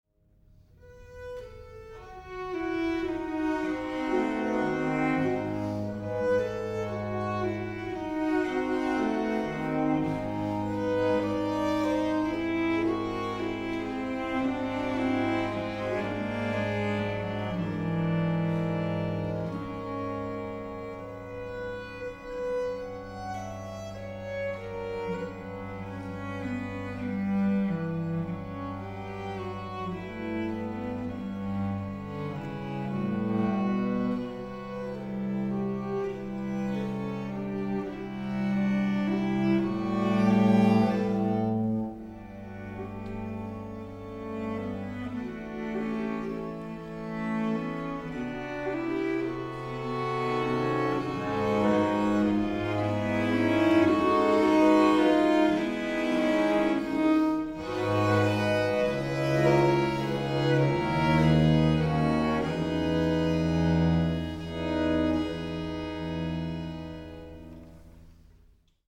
viola da gamba quartet